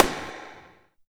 90 SNARE 2-R.wav